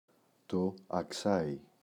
αξάϊ, το [a’ksai]